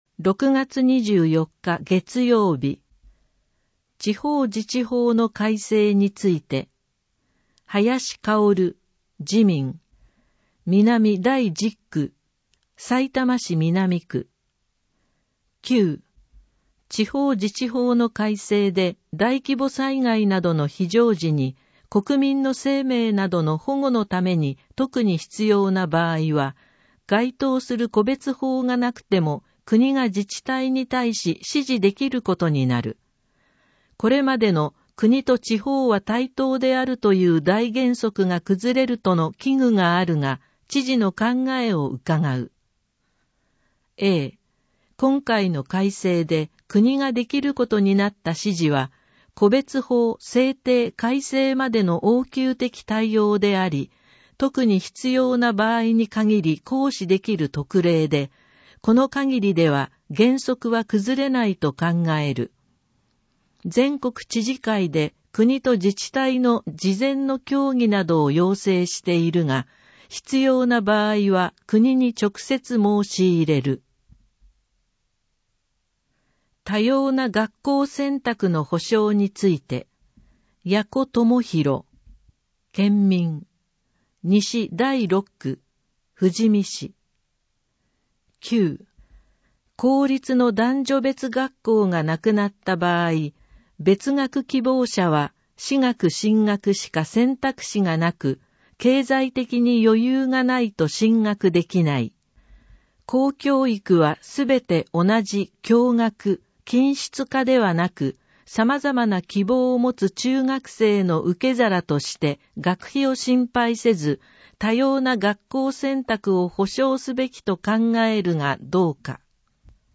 「Windows Media Player」が立ち上がり、埼玉県議会だより 178号の内容を音声（デイジー版）でご案内します。